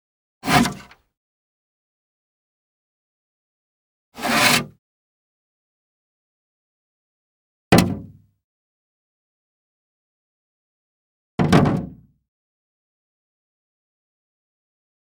Garbage Can Metal Waste Paper Basket Pick Up Sound
household